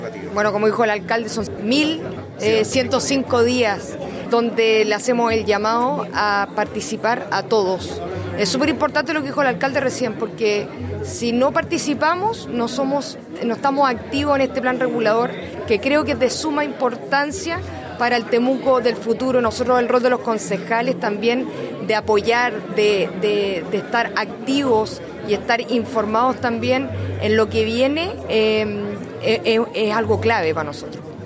La actividad, realizada en la sala de Concejo de la Municipalidad de Temuco, reunió a autoridades comunales, regionales, ancestrales, dirigentes y dirigentas sociales, además de representantes del mundo público y privado.
Micaela-Becker-concejala-Temuco-.mp3